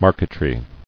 [mar·que·try]